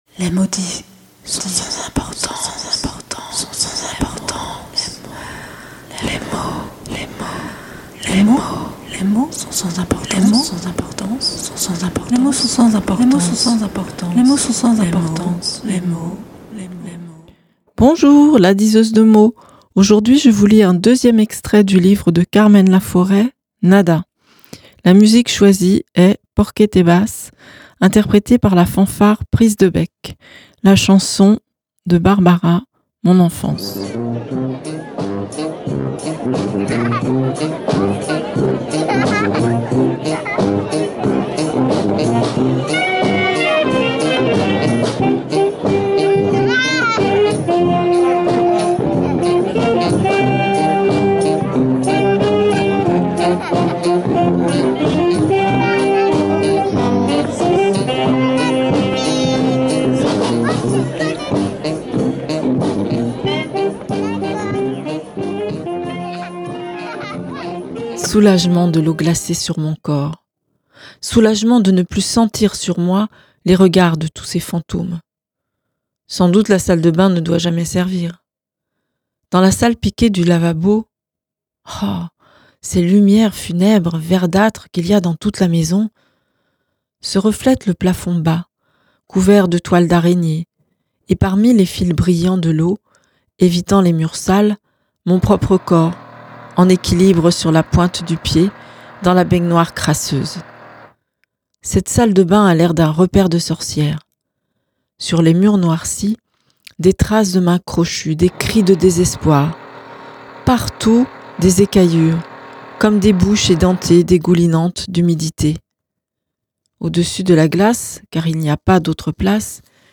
2nd extrait de Nada de Carmen Laforet